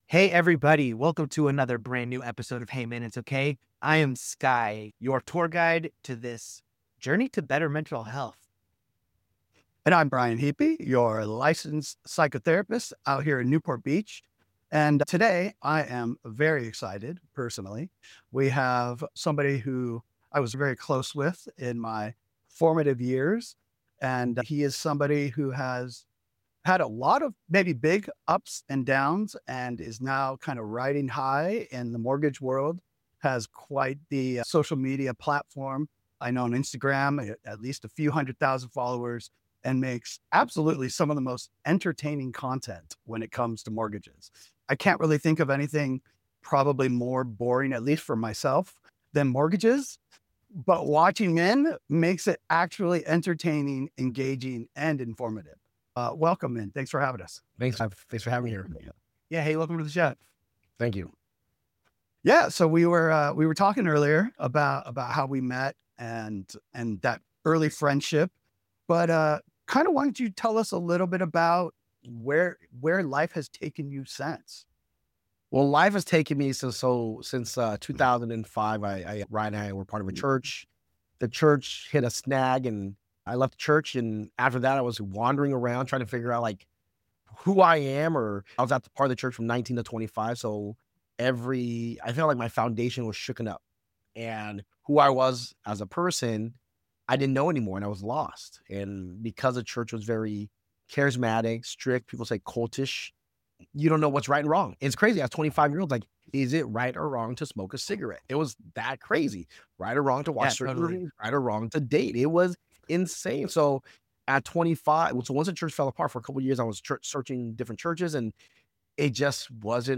to share a raw conversation about shame, success, financial collapse, and choosing to stay alive.